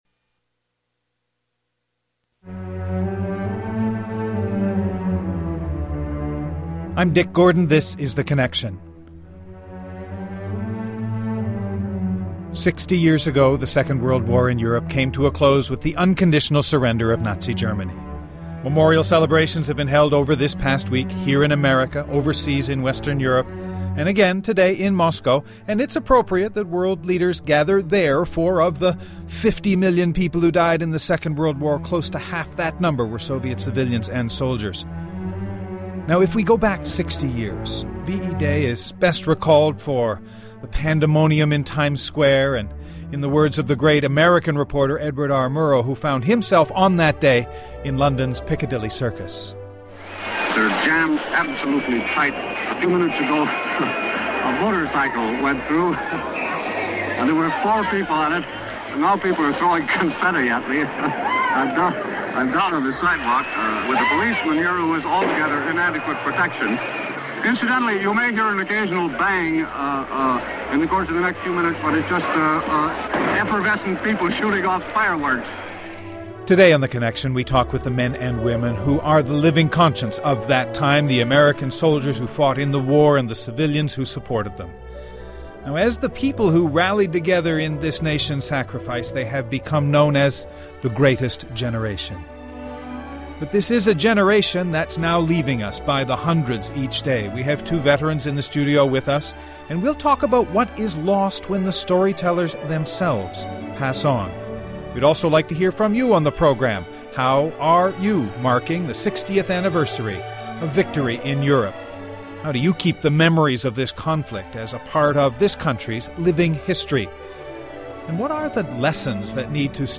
Today we commemorate victory in Europe with two veterans who were there and hear how the lessons of World War II will be remembered when the veterans are no longer around to tell the stories.